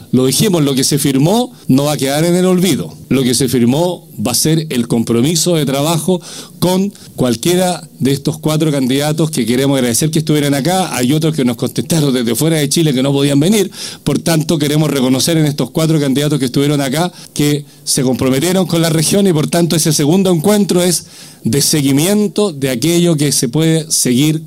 El gobernador regional de Los Lagos, Patricio Vallespín, valoró el diálogo con los candidatos presidenciales y los llamó a comprometerse con las regiones.